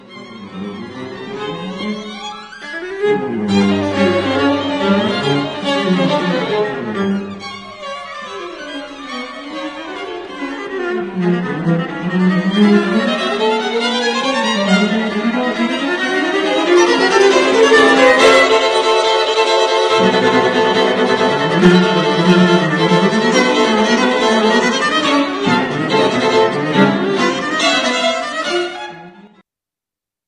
stri-ng-qu-a-rtet-no5-fin-al.mp3